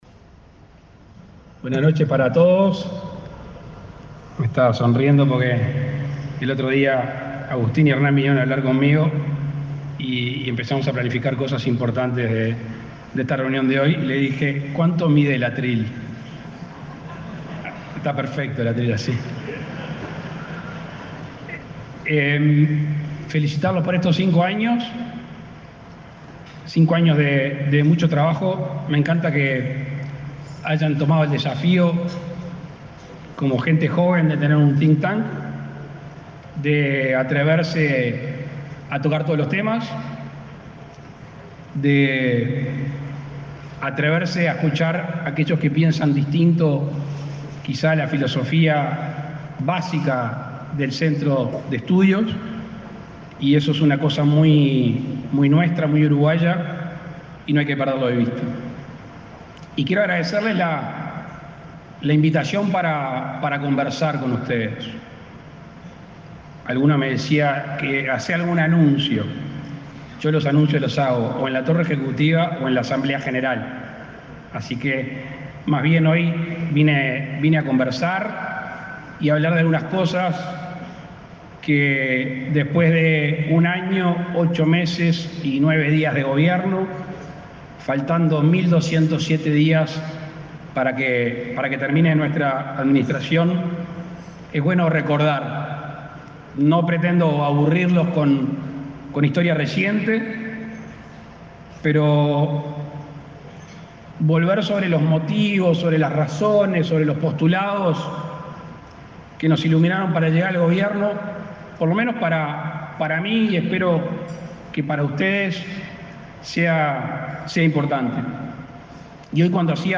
Palabras del presidente de la República, Luis Lacalle Pou